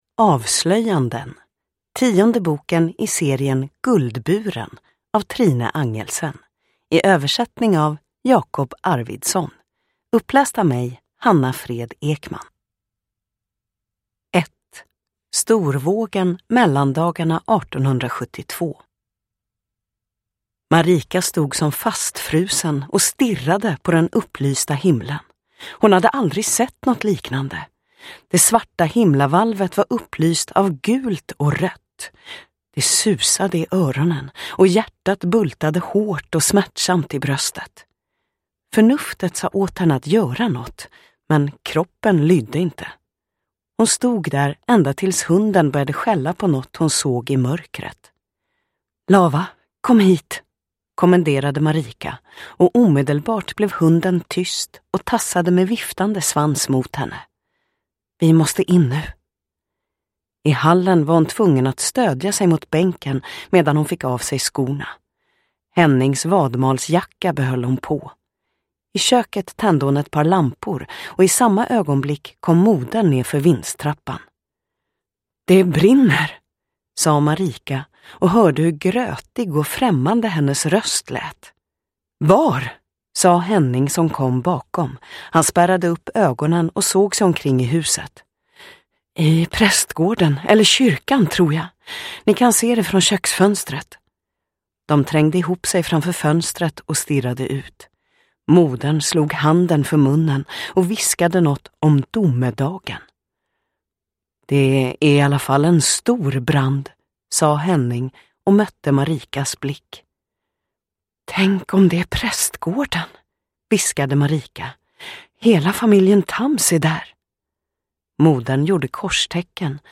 Avslöjanden – Ljudbok